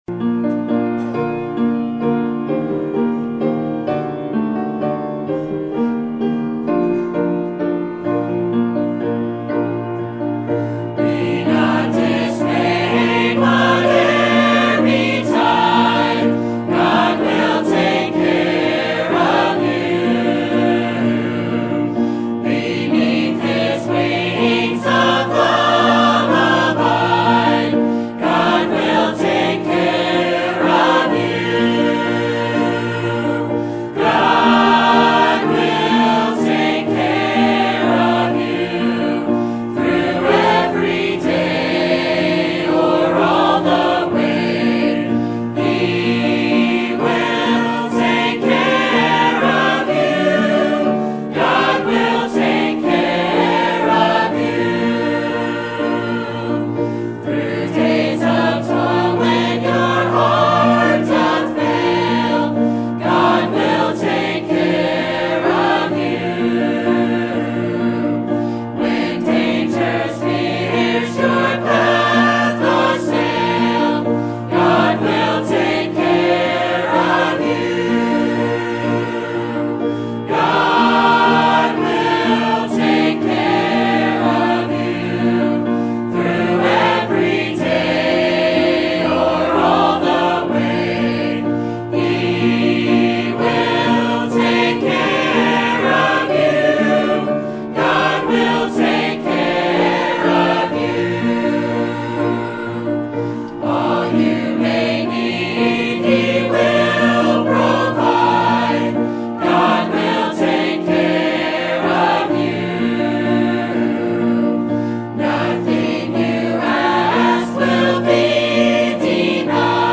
Sermon Topic: General Sermon Type: Service Sermon Audio: Sermon download: Download (27.68 MB) Sermon Tags: Philippians Paul Joy Trials